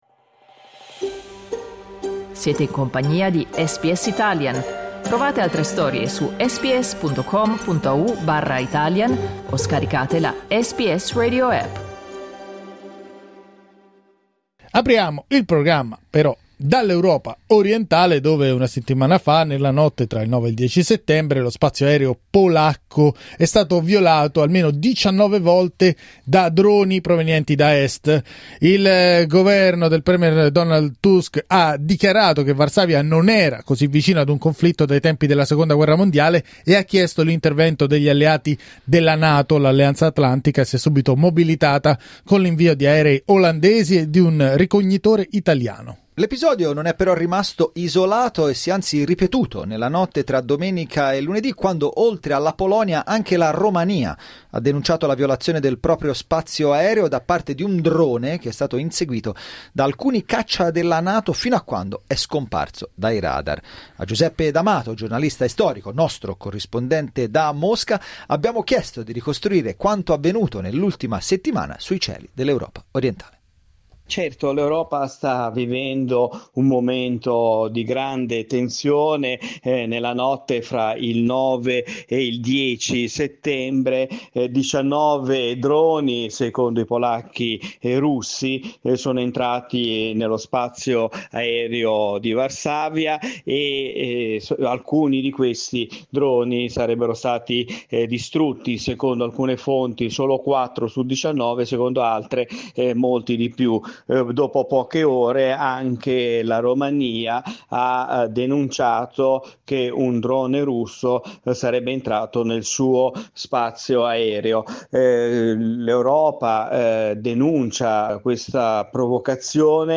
SBS in Italiano
Clicca sul tasto "play" in alto per ascoltare il resoconto del giornalista